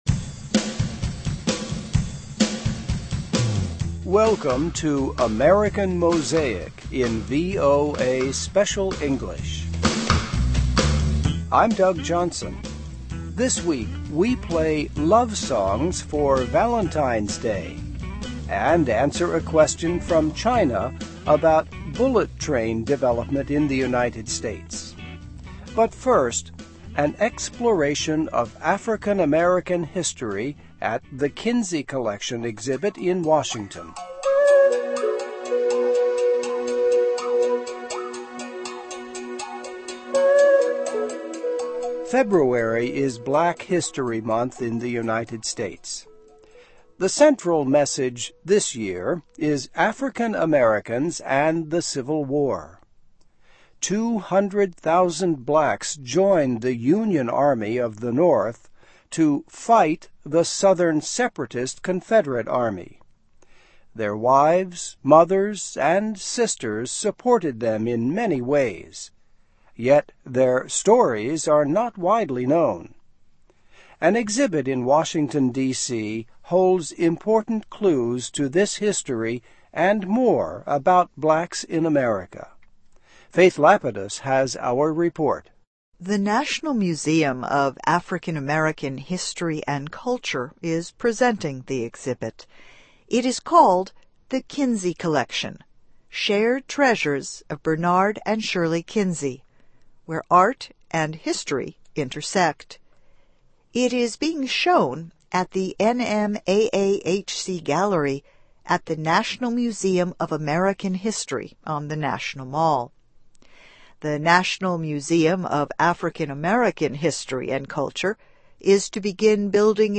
Music for Valentine’s Day. And a question from China about talk of bullet trains in America | AMERICAN MOSAIC